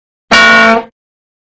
7. Удар